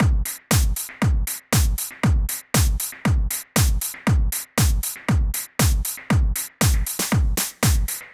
28 Drumloop PT2.wav